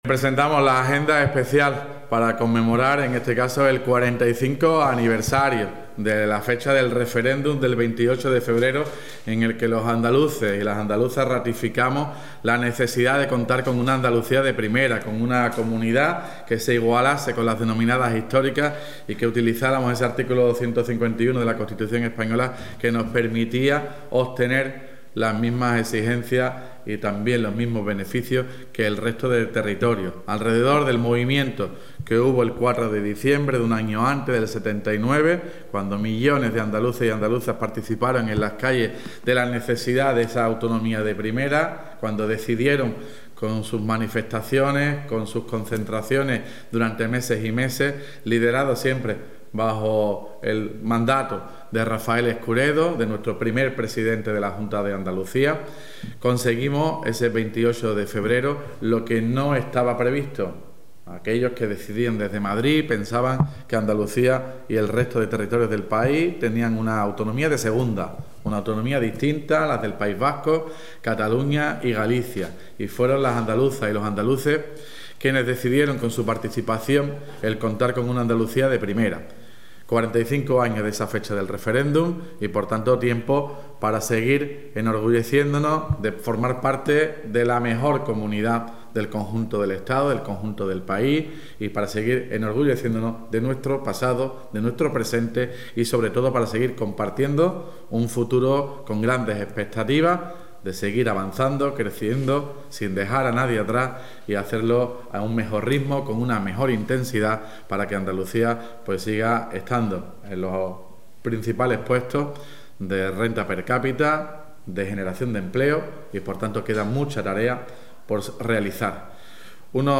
RP PRESENTACIÓN  DIA ANDALUCIA TOTAL ALCALDE 21 FEB 25.mp3